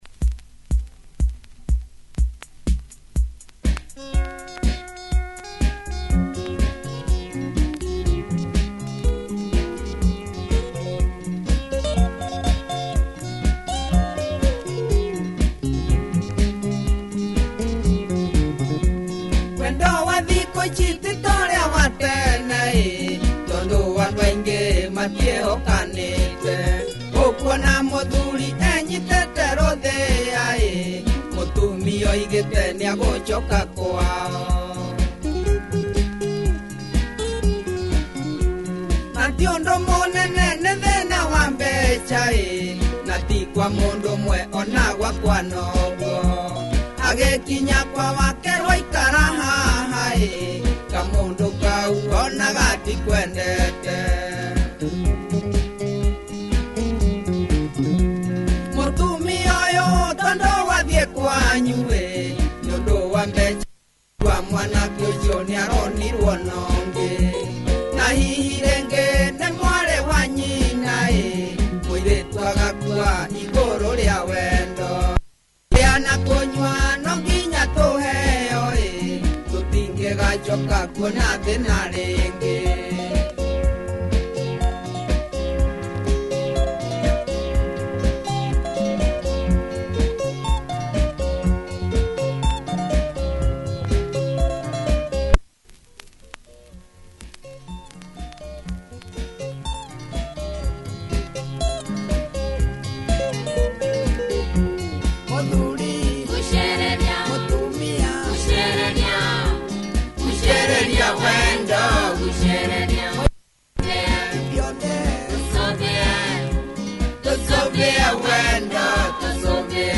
cool beat! That back-beat sound like a drummachine?